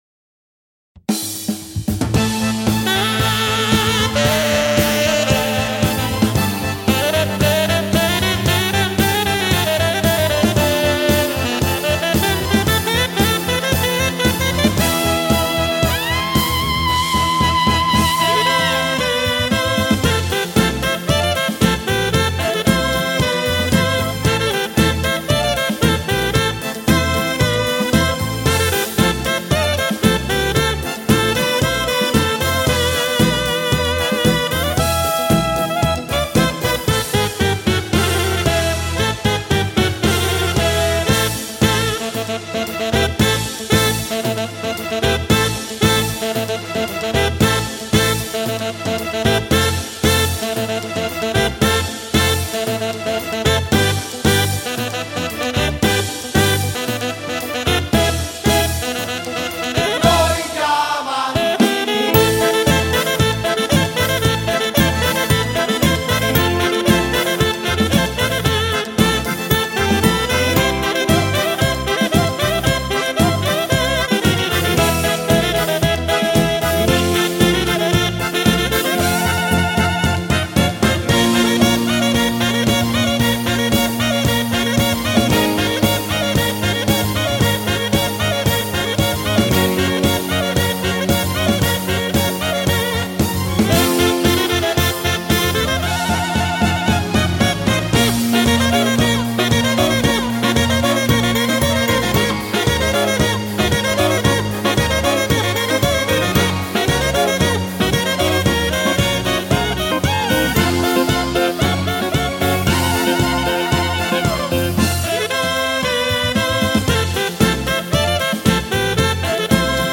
versione strumentale